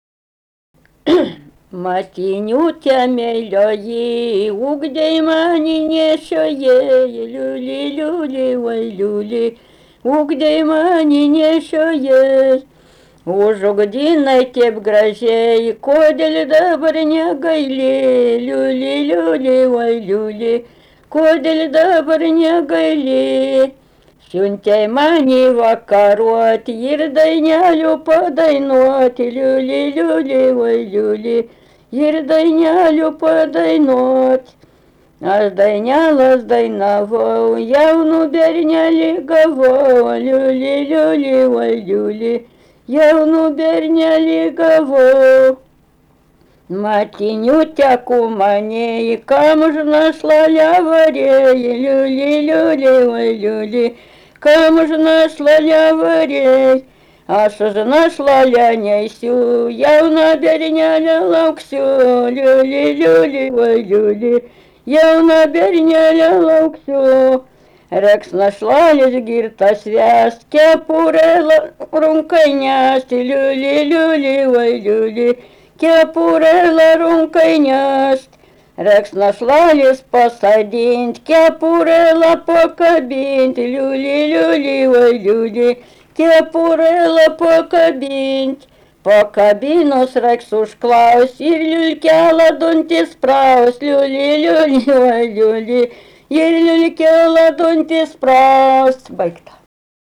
daina
Ryžiškė
vokalinis